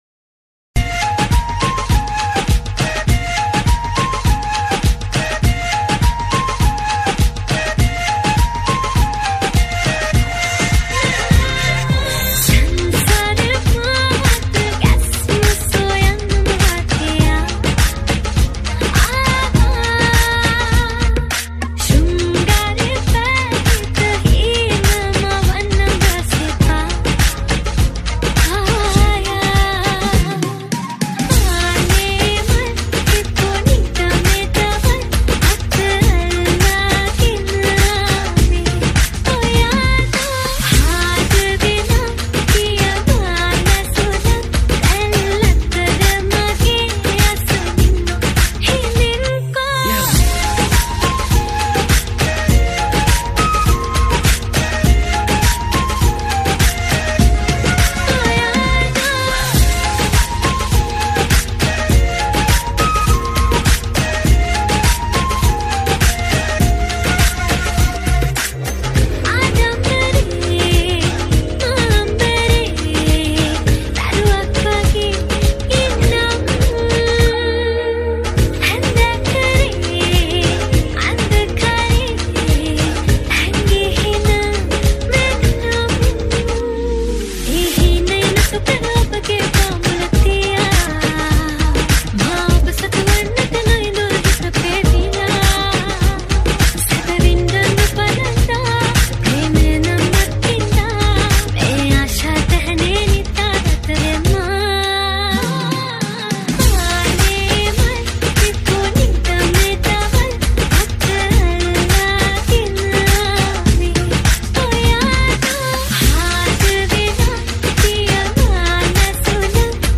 High quality Sri Lankan remix MP3 (3.2).